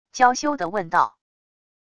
娇羞的问道wav音频